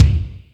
Lotsa Kicks(12).wav